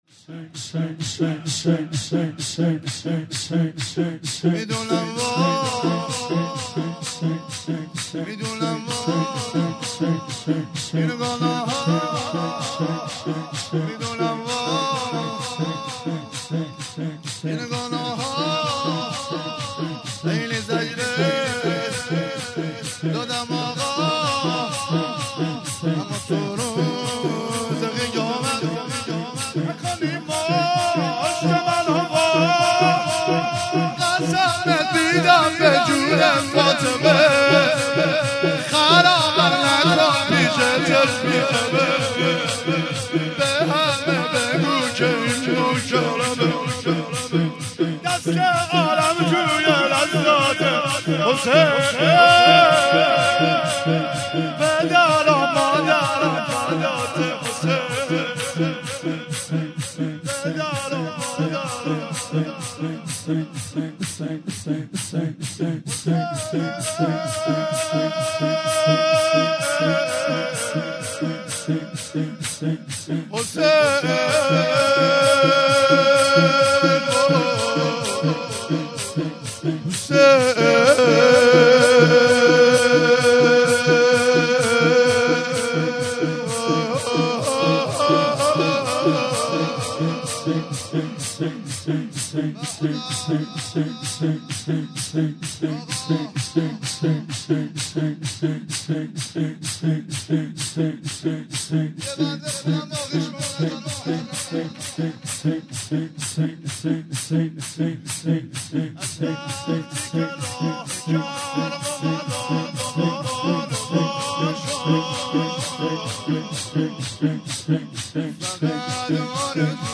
14- میدونم وای - شور